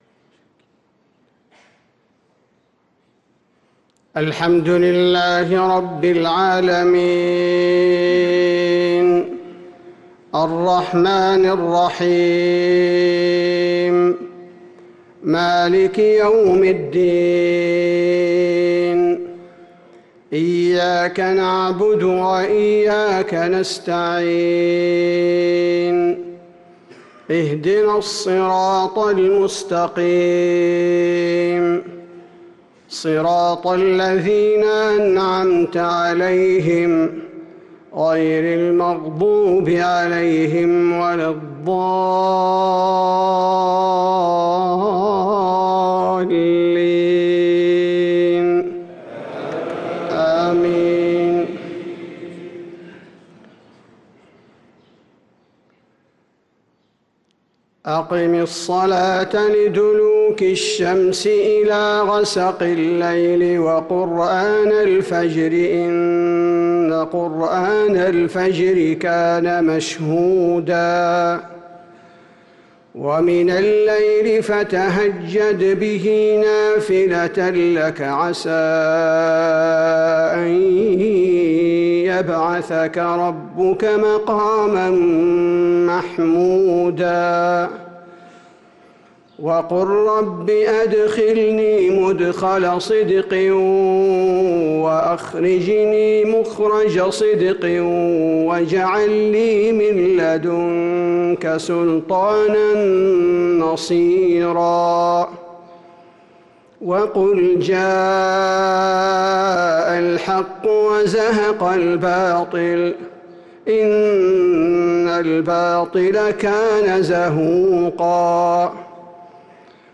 صلاة العشاء للقارئ عبدالباري الثبيتي 4 شعبان 1445 هـ
تِلَاوَات الْحَرَمَيْن .